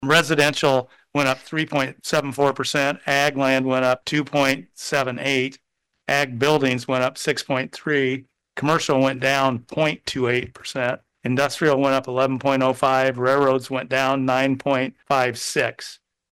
The board of supervisors acknowledged receipt of the county’s Fiscal Year (FY) 2026 valuation report at their meeting this (Monday) morning. Board Chair Scott Johnson details where the figures currently stand.